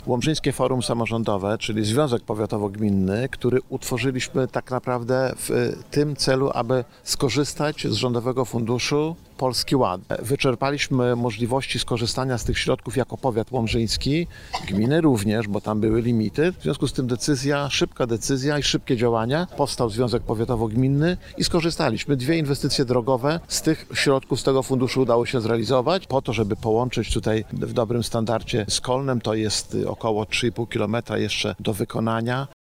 Starosta łomżyński Lech Szabłowski przypomniał, że dofinansowanie na remont drogi pozyskano z rządowego programu Polski Ład.